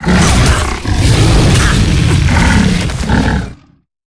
Sfx_creature_snowstalker_cinematic_playerescape_01.ogg